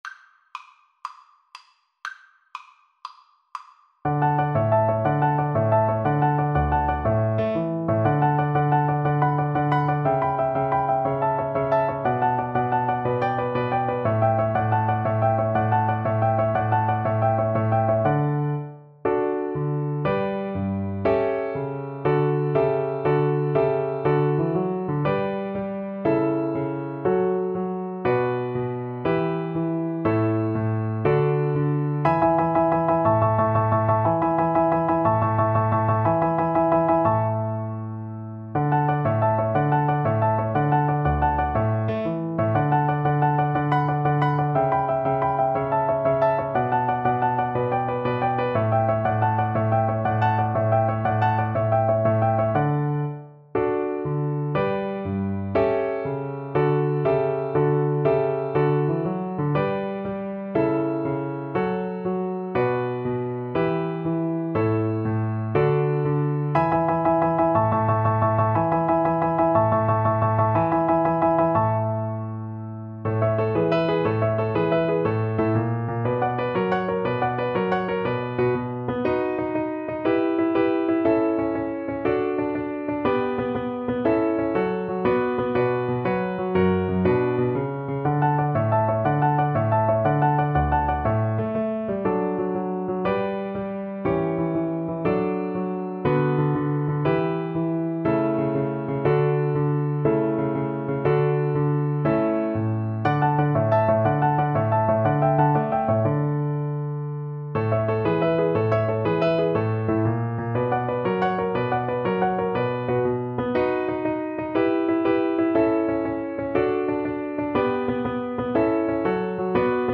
12/8 (View more 12/8 Music)
. = 120 Allegro (View more music marked Allegro)
Classical (View more Classical Violin Music)